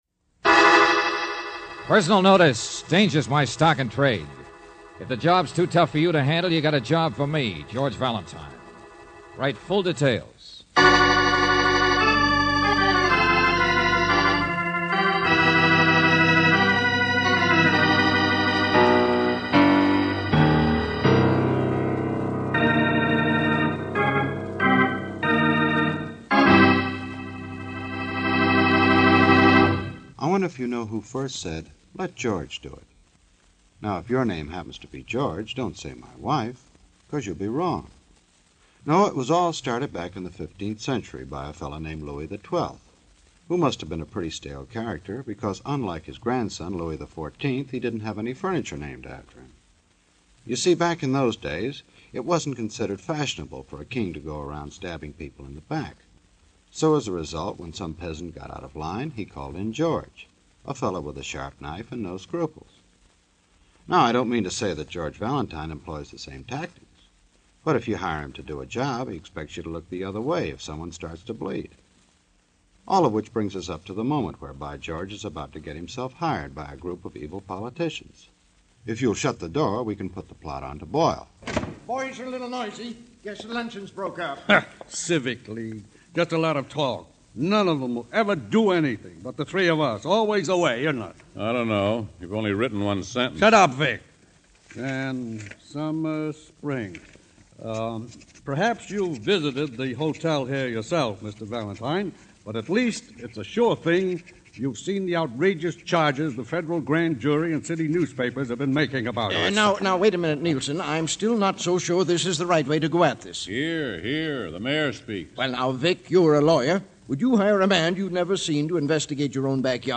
Let George Do It is an American radio drama series produced from 1946 to 1954 by Owen and Pauline Vinson. Bob Bailey starred as private investigator George Valentine; Olan Soule voiced the role in 1954.
The program then changed into a suspenseful tough guy private eye series.